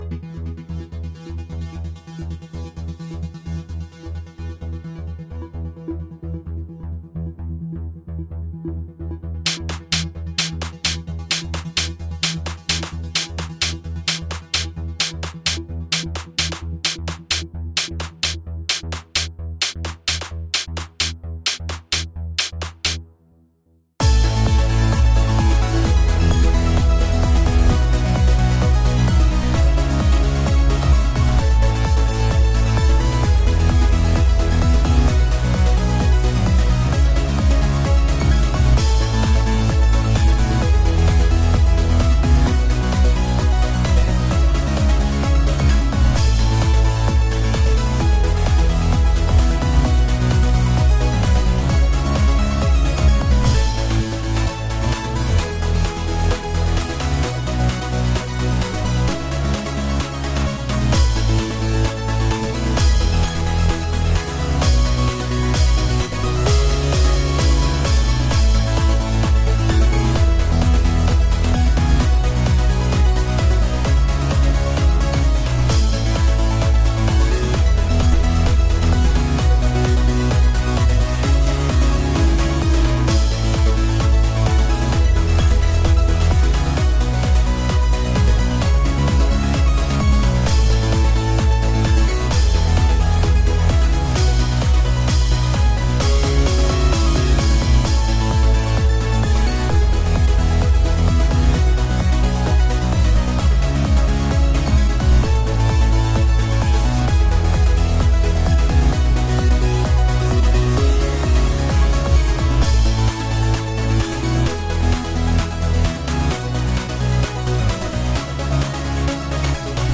The Conversion Anthem (First Electronic Production With Macbook Pro)